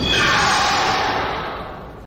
File:Dolcross roar.ogg
Dolcross_roar.ogg